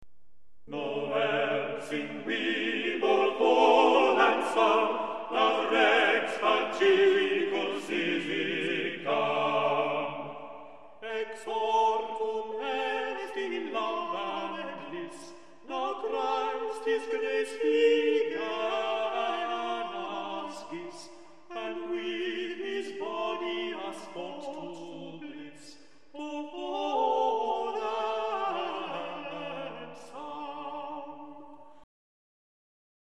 Nowell Sing We [Exortum est in Love and Lysse] (Middle English Lyric; Christmas Carol)